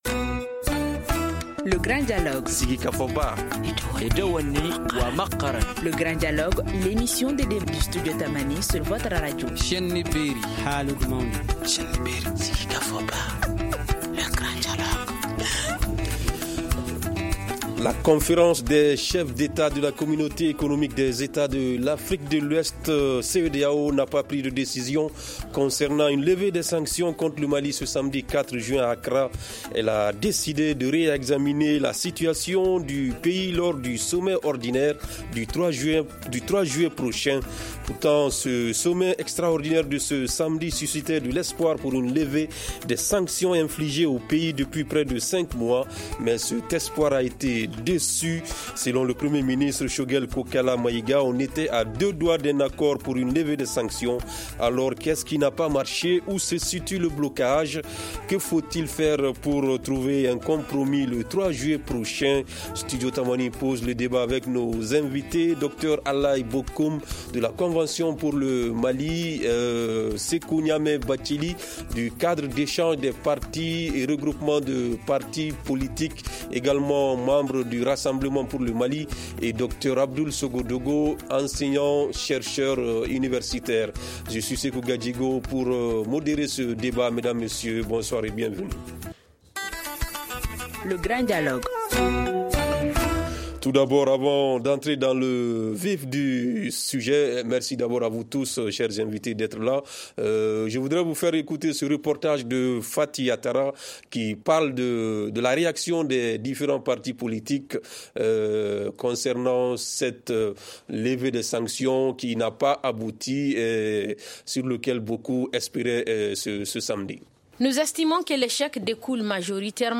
Studio Tamani pose le débat avec nos invités :